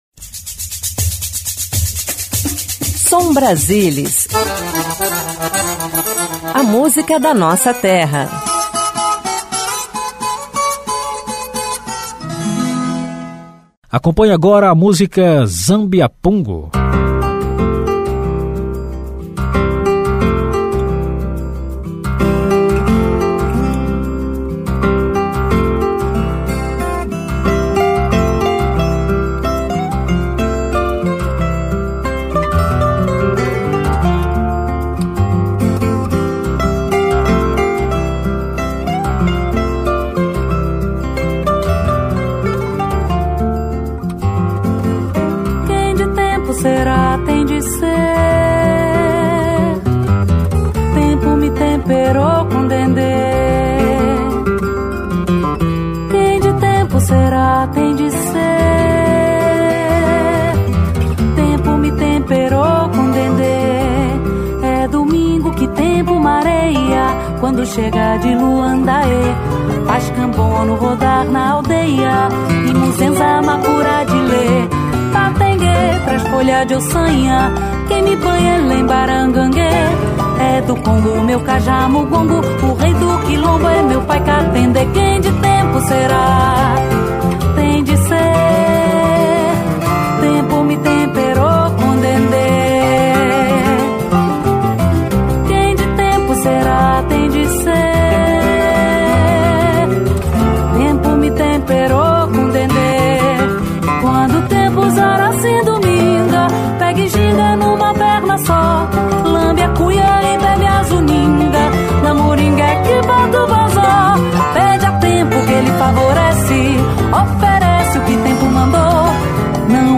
Jazz MPB Violão Música instrumental